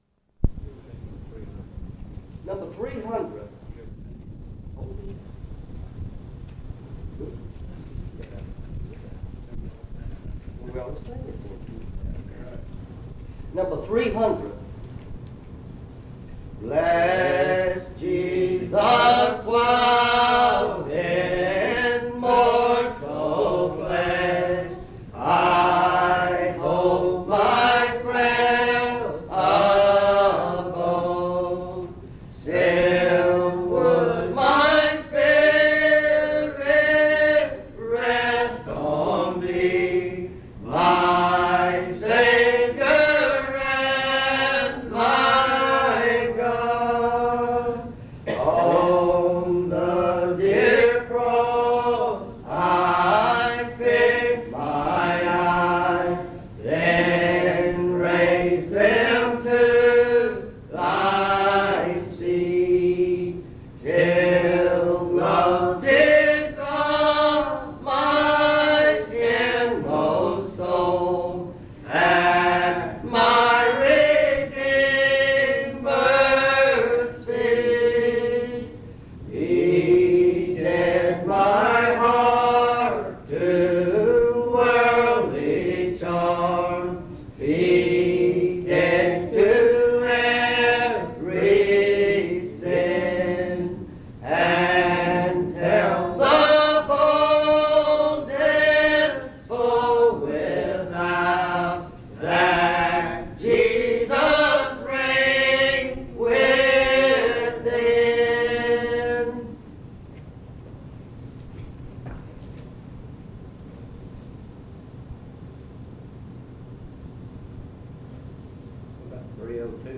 Home Service